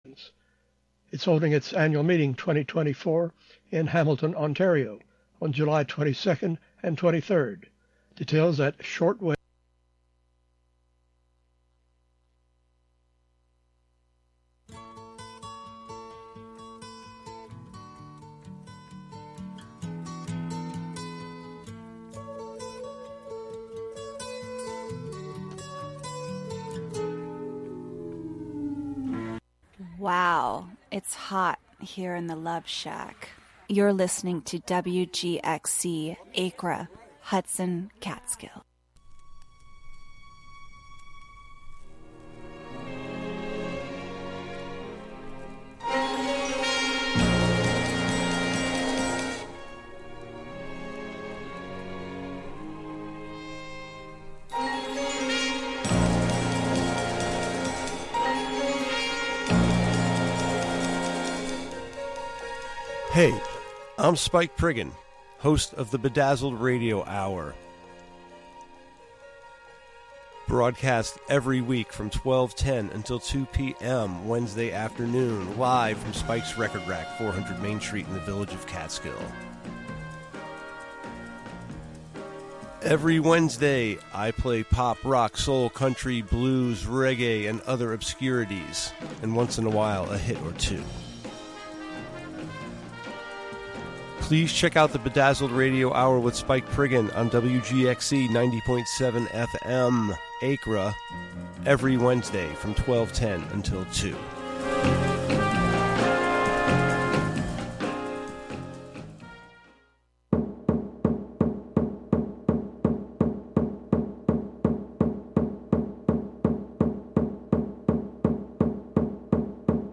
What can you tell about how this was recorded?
The show is a place for a community conversation about issues, with music, and more. Saturday the emphasis is more on radio art, and art on the radio.